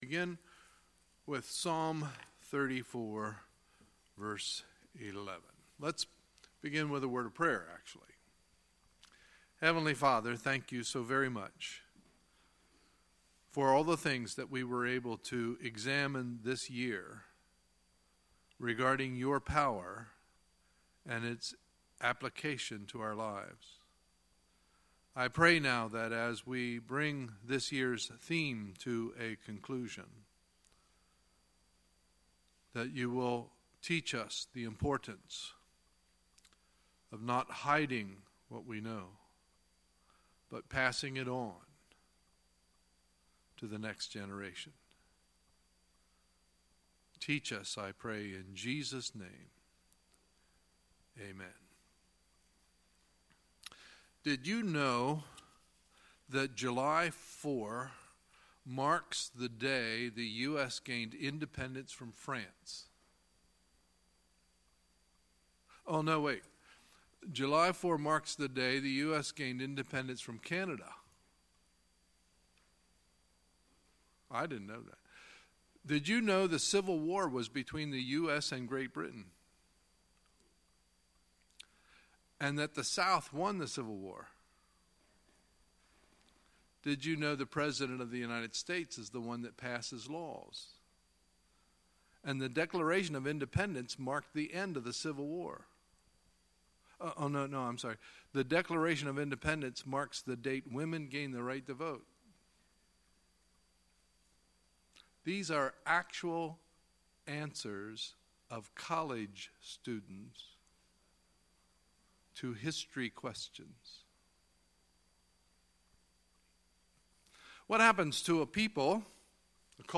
Sunday, November 26, 2017 – Sunday Morning Service